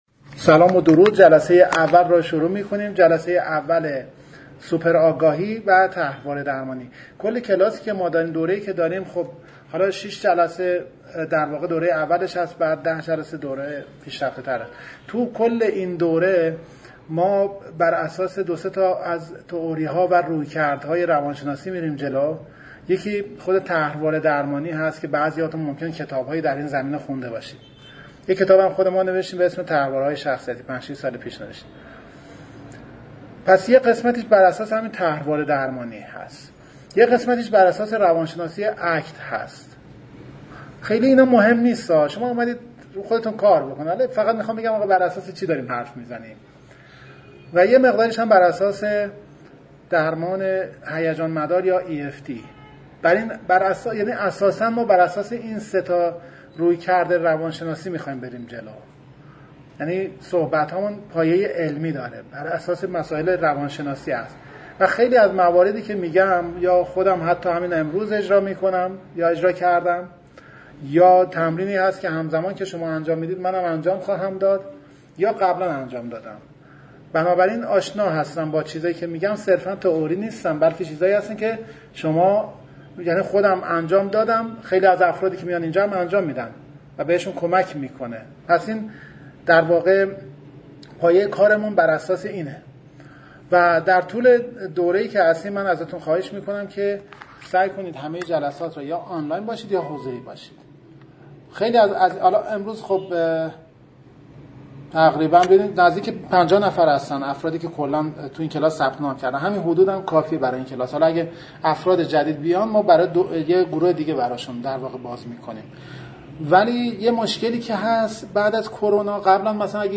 ویس جلسه اول دوره طرحواره درمانی ترم اول